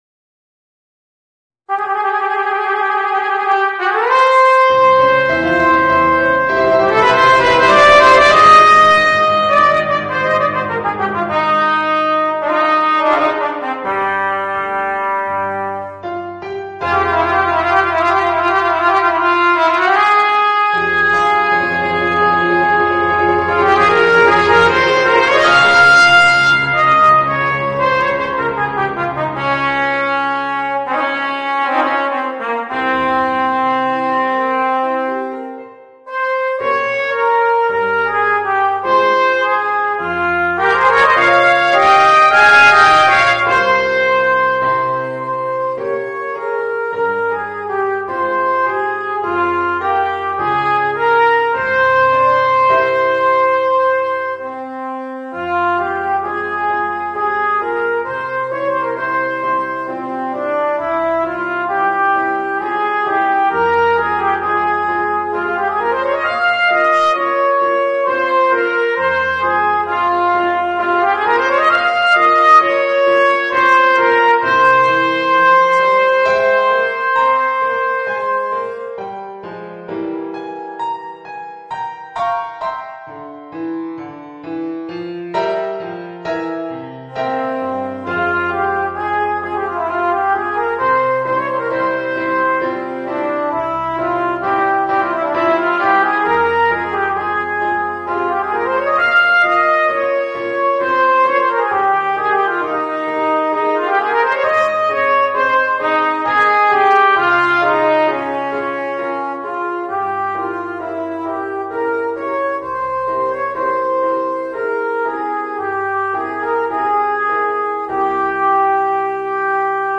Voicing: Cornet and Piano